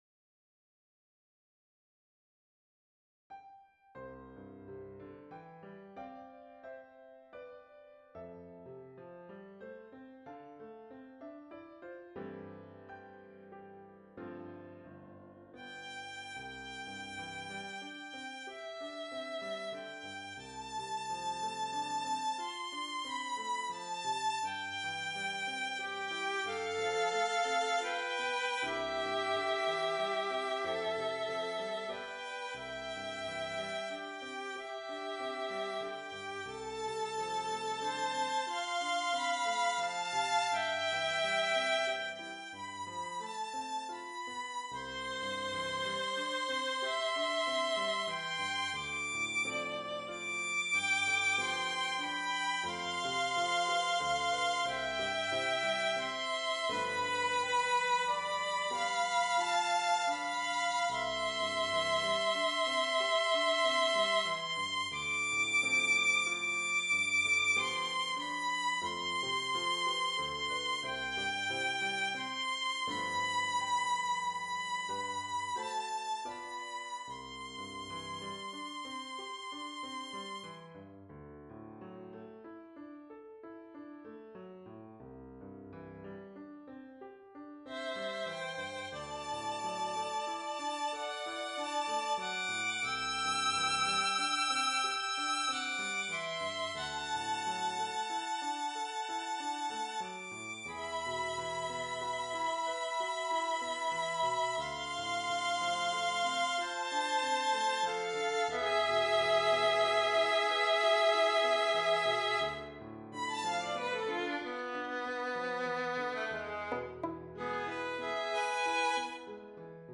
Violin and Piano
Song (ternary)